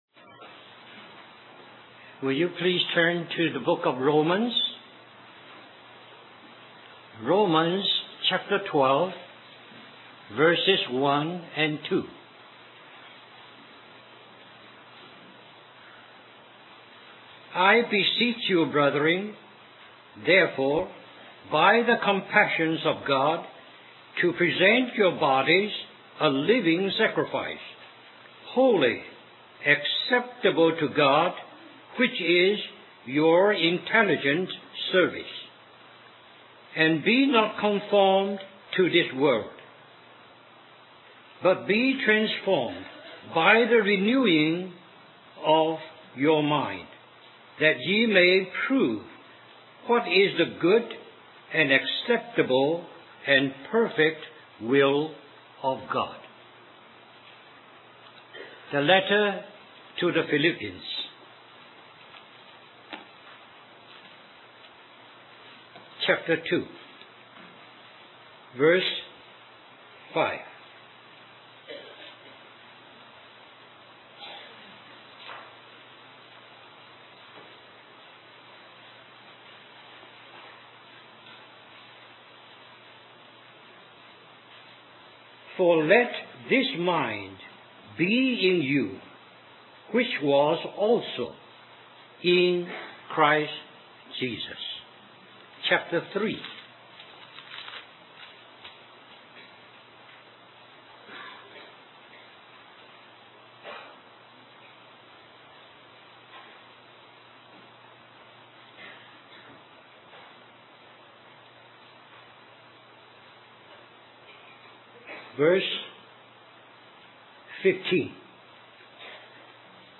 2003 Harvey Cedars Conference Stream or download mp3 Summary The battle that the enemy is waging against the saints is the battle of the mind.